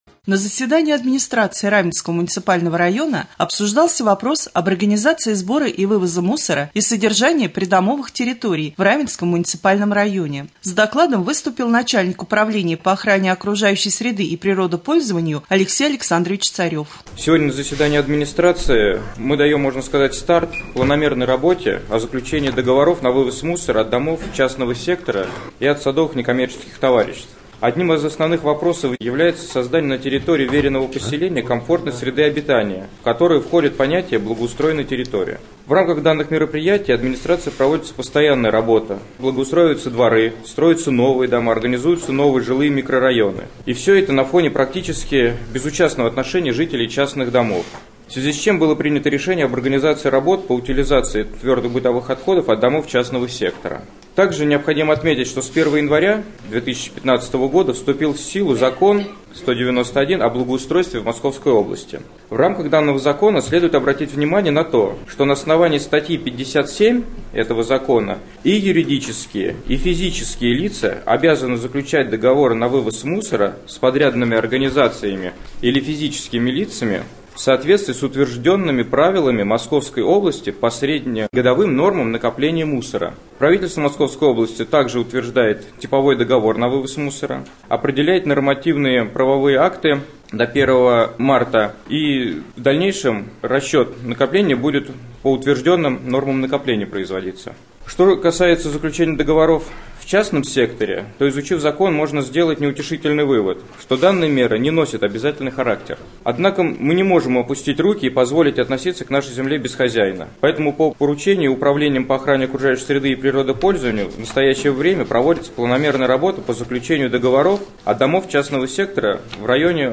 20.02.2015г. в эфире Раменского радио - РамМедиа - Раменский муниципальный округ - Раменское